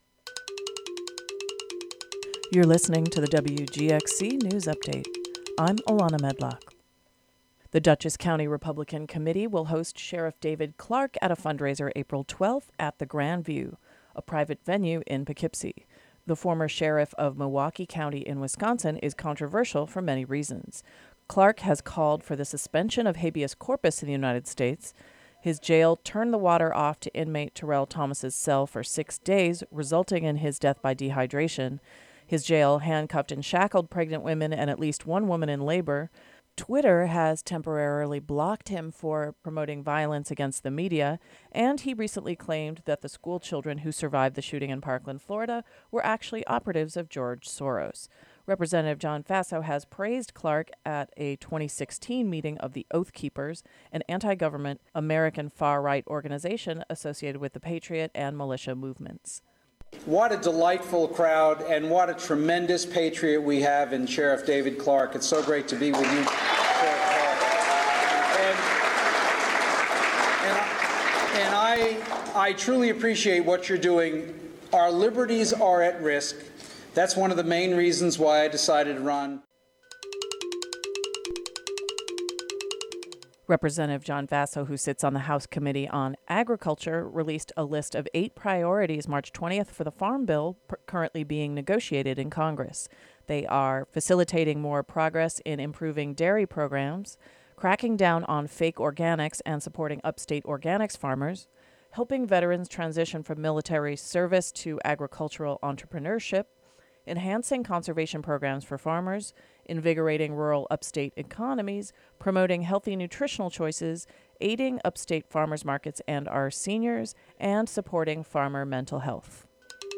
The local news update for the Hudson Valley.